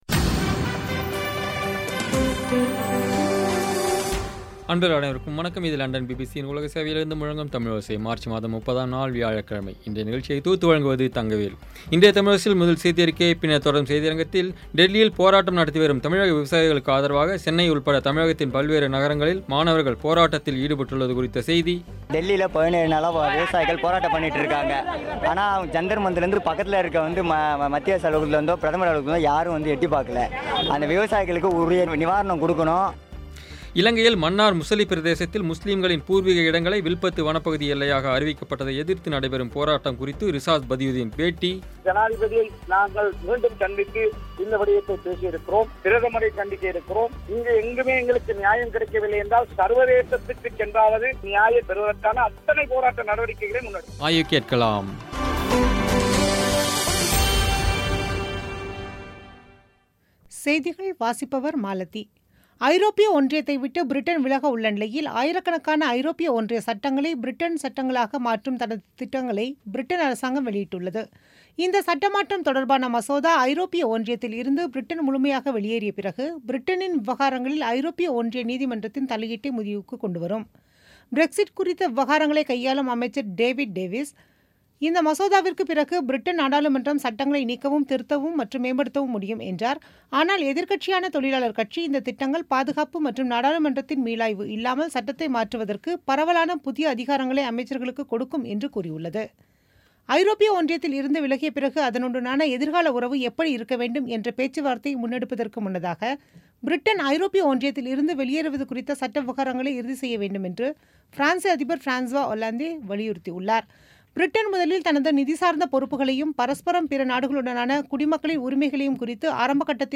இலங்கையில் மன்னார் முசலி பிரதேசத்தில் முஸ்லிம்களின் பூர்விக இடங்களை வில்பத்து வனப்பகுதி எல்லையாக அறிவிக்கப்பட்டதை எதிர்த்து நடைபெறும் போராட்டம் குறித்து ரிசாத் பதியுதீன் பேட்டி